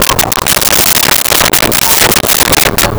Chickens In Barn 01
Chickens in Barn 01.wav